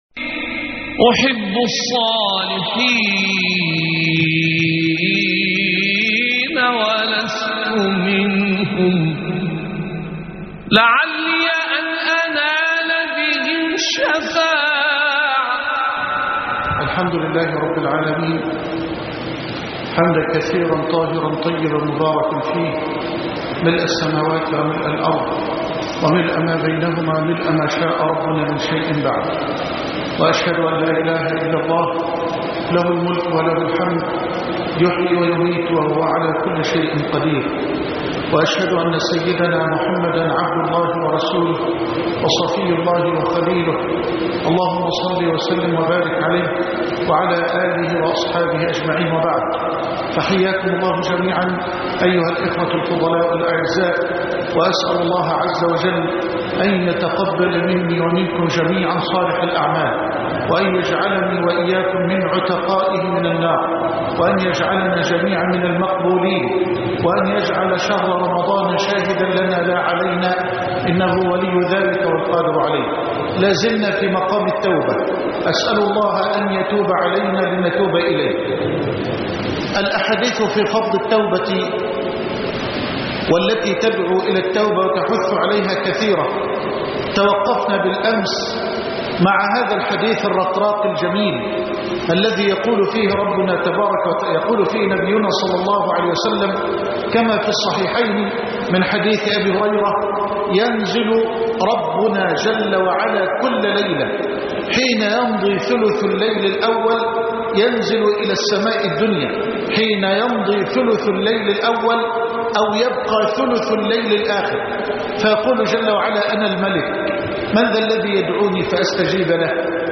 رمضان شهر التوبة (7/8/2011) خاطرة الفجر - فضيلة الشيخ محمد حسان